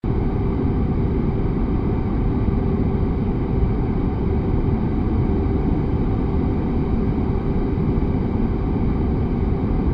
idle.ogg